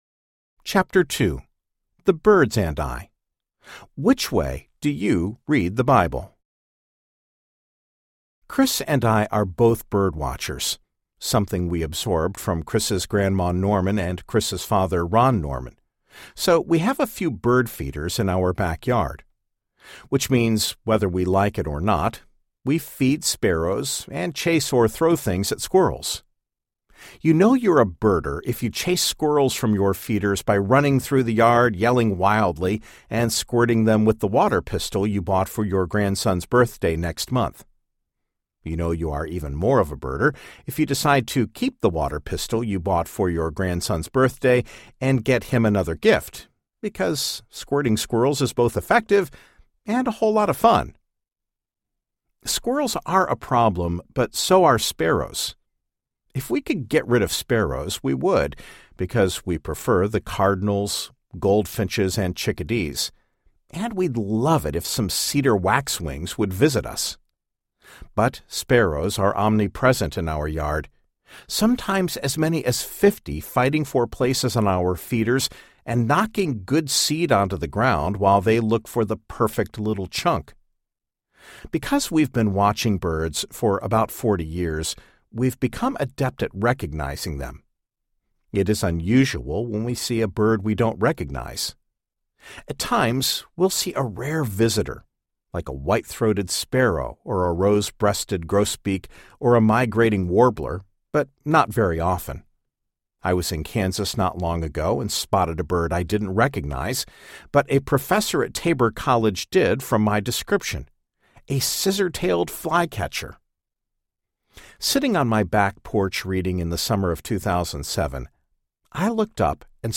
The Blue Parakeet, 2nd Edition Audiobook